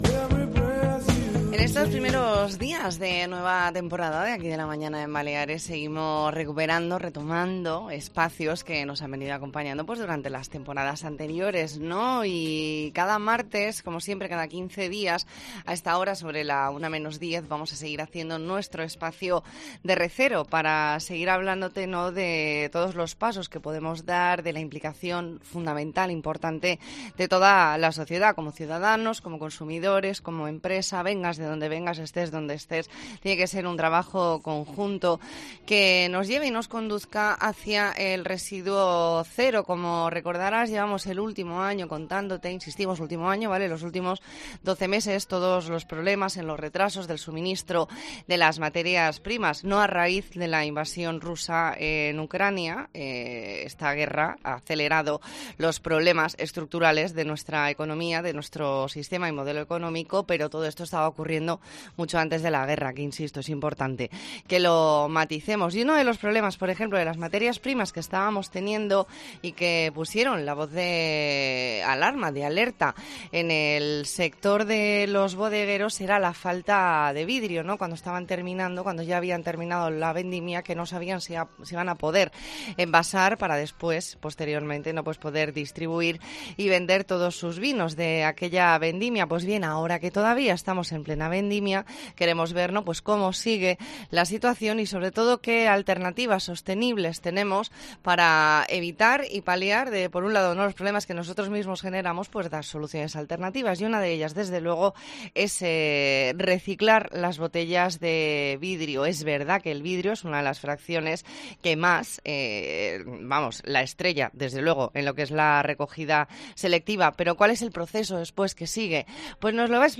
ntrevista en La Mañana en COPE Más Mallorca, martes 6 de septiembre de 2022.